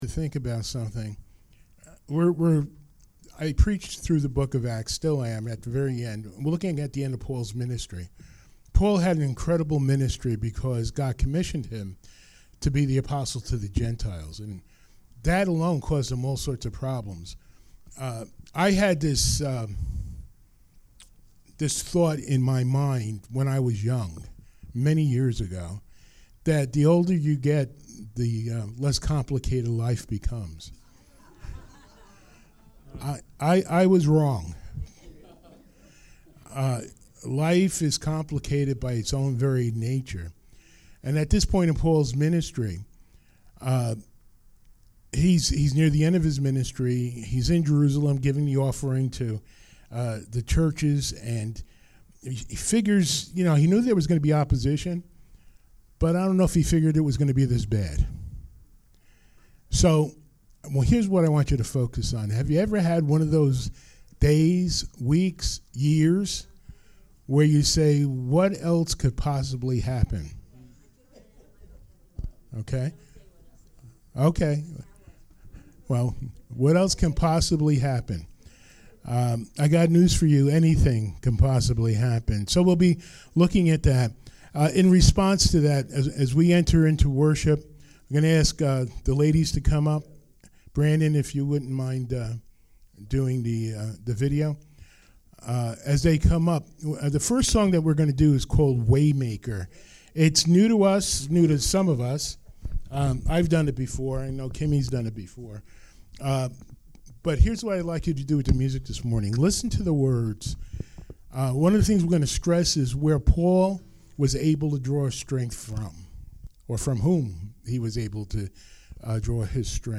Acts 21:27-22:29 Service Type: Sunday Morning Worship Well